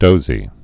(dōzē)